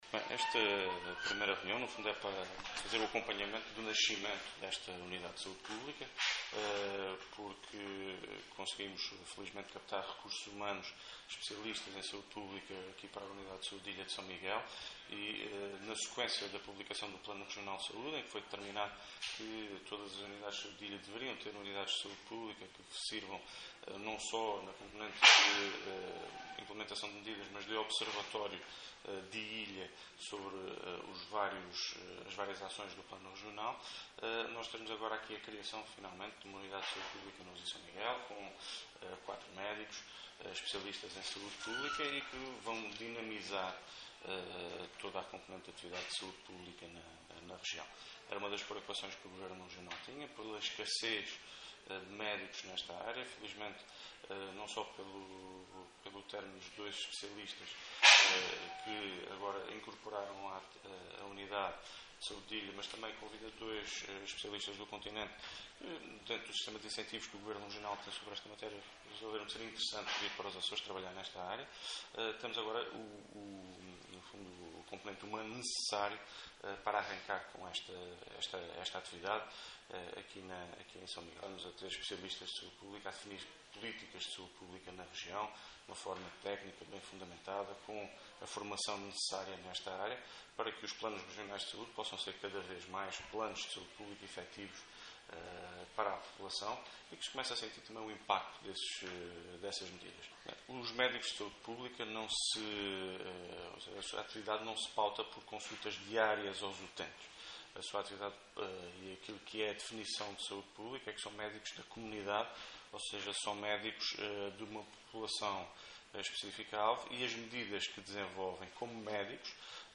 Na primeira reunião desta entidade, que decorreu no Centro de Saúde da Ribeira Grande, Luís Cabral salientou que a sua criação foi possível porque “o Executivo Açoriano conseguiu, felizmente, captar recursos humanos especialistas nesta área”.